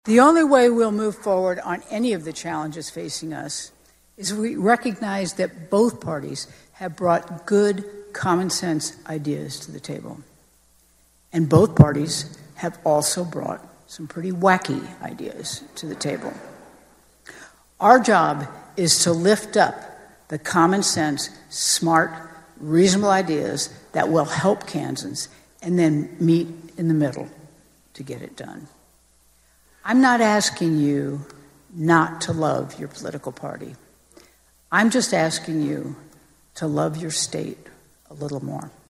With a bigger Republican supermajority in the Statehouse than she has ever faced, Democrat Governor Laura Kelly pledged a bipartisan approach on several issues as part of her State of the State Address on Wednesday.